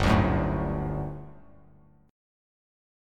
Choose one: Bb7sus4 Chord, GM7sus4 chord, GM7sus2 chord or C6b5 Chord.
GM7sus2 chord